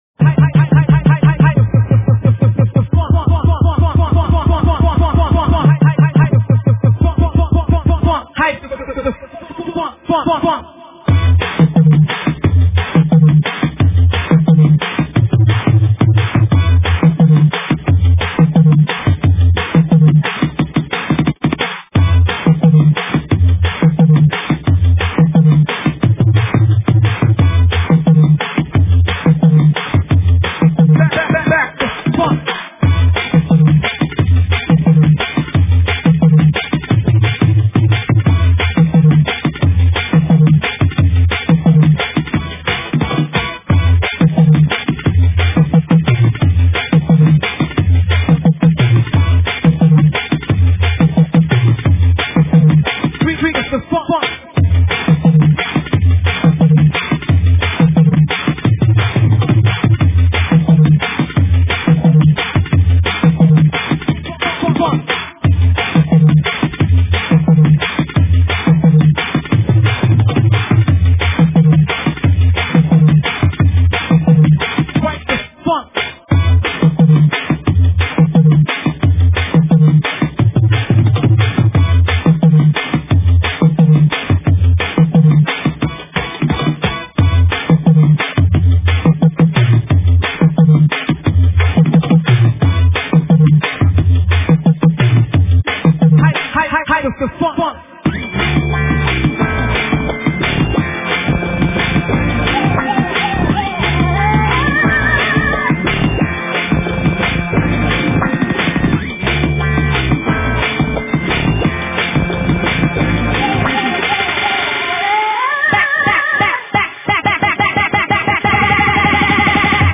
Drum&Bass, Dubstep